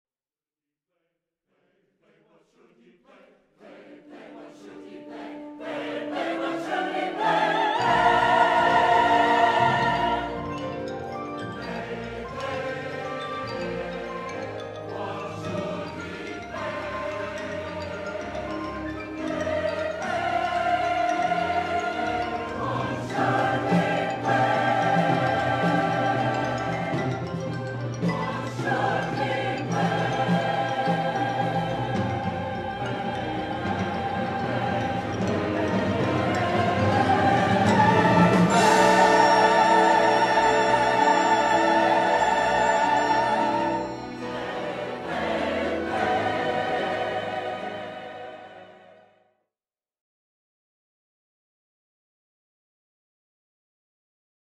Scored for: soprano and baritone solos, SATB, orchestra